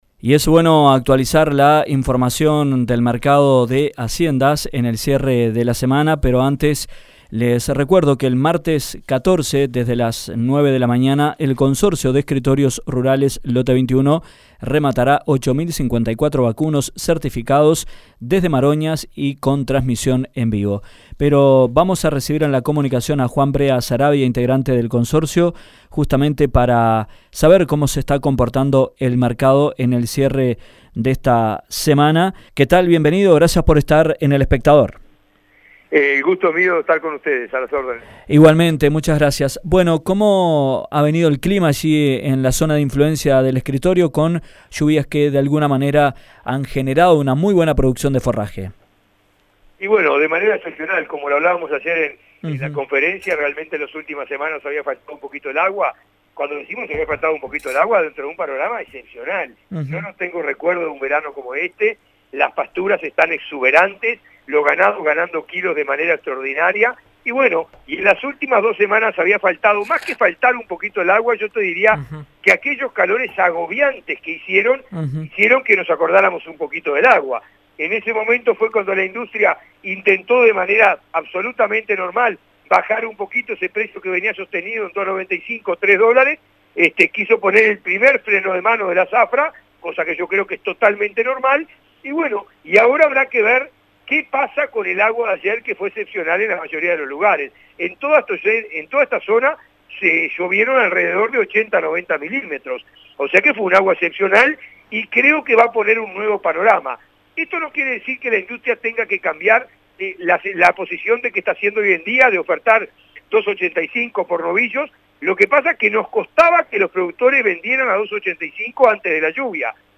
El régimen de lluvias y temperaturas propicias generan un buen nivel de pasturas. En conversación